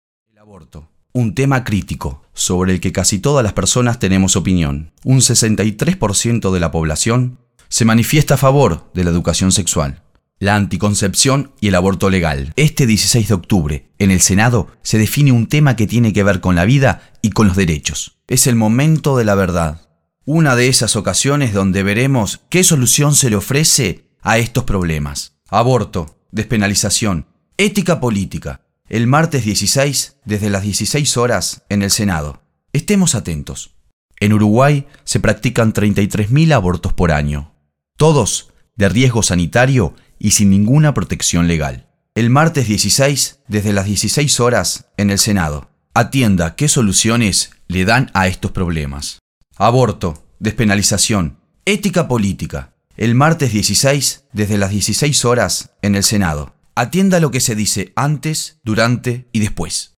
radiales específicamente sobre el debate en el Senado.
Spots-radiales-2007.mp3